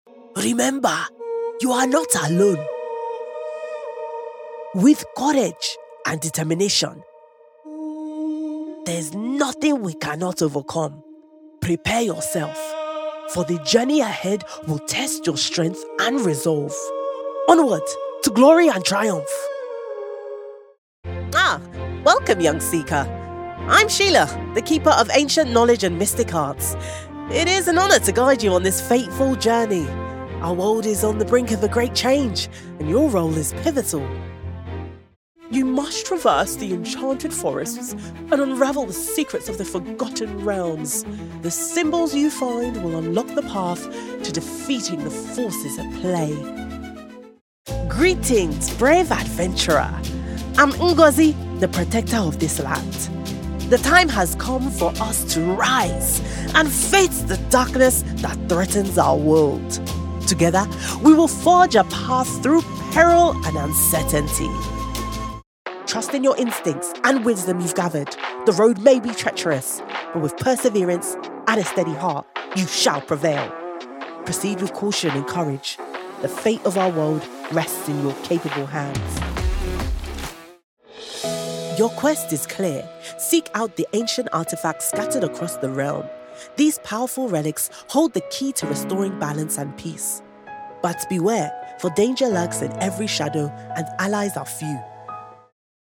Video Game Showreel
Her youthful energy brings any project to life!
Female
Neutral British
Bright
Friendly